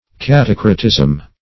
catacrotism - definition of catacrotism - synonyms, pronunciation, spelling from Free Dictionary